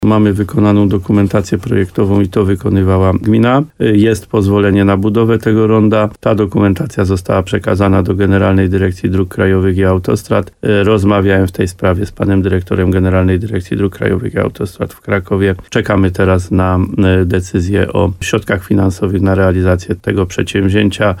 Jak mówił w programie Słowo za Słowo w radiu RDN Nowy Sącz wójt gminy Adam Wolak, ze strony urzędników jest już wszystko przygotowane.